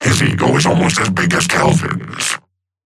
Seven voice line - His ego is almost as big as Kelvin's.